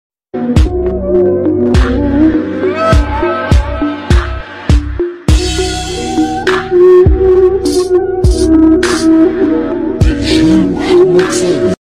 poetry music background Sad poetry status